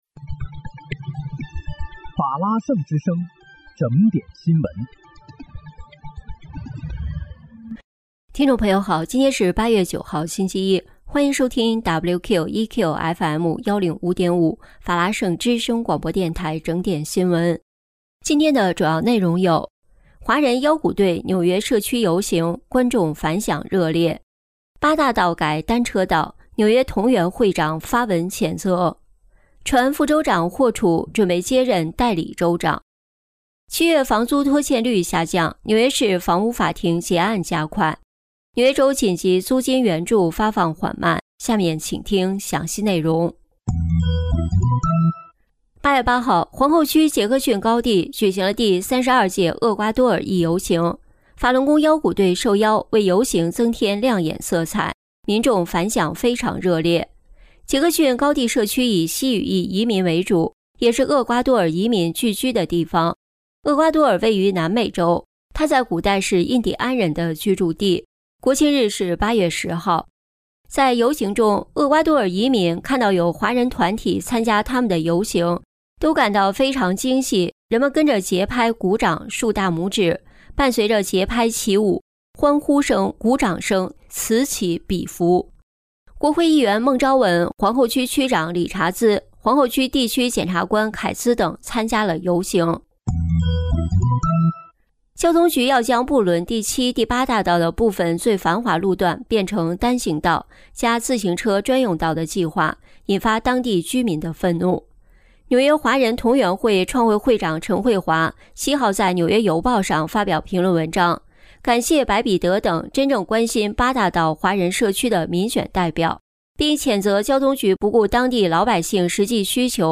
8月9日（星期一）纽约整点新闻